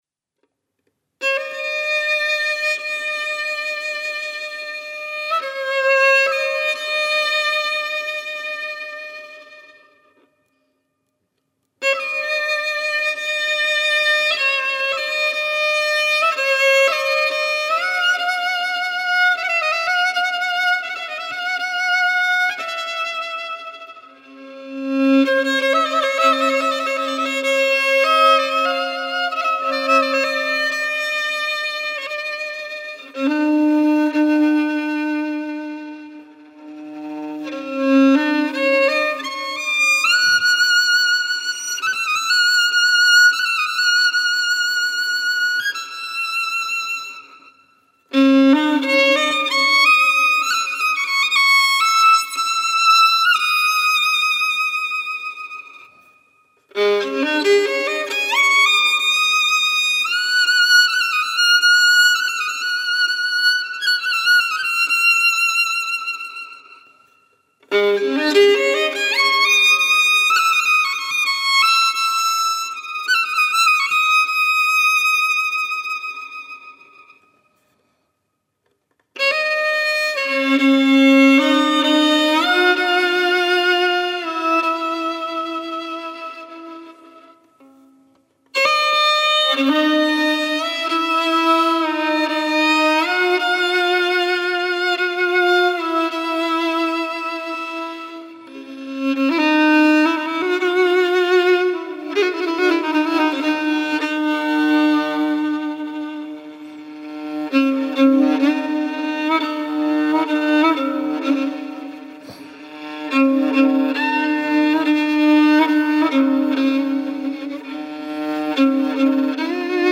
Download track7.mp3 سایر دسته بندیها دعا و نیایش با موسیقی 2 11686 reads Add new comment Your name Subject دیدگاه * More information about text formats What code is in the image?